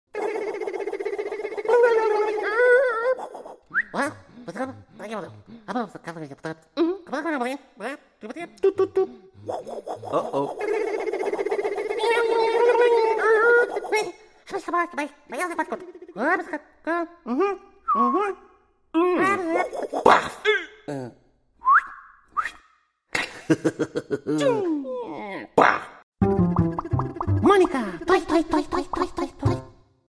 telepon hape sound effects free download